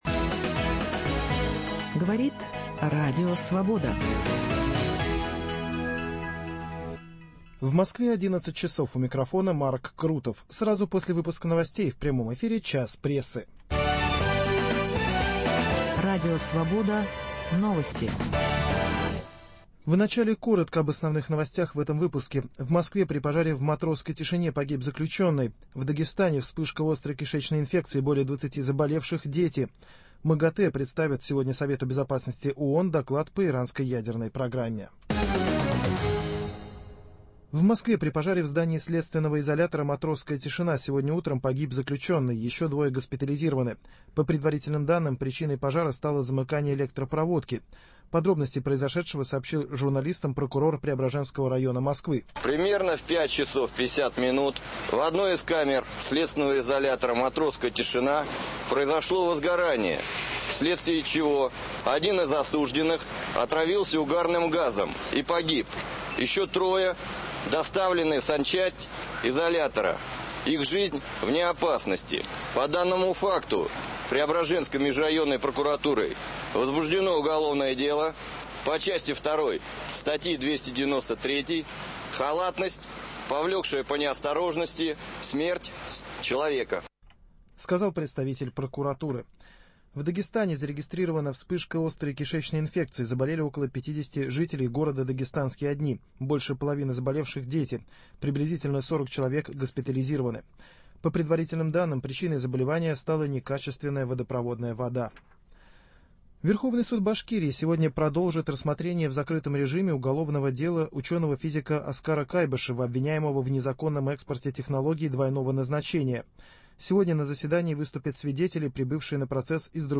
в беседе с депутатом Госдумы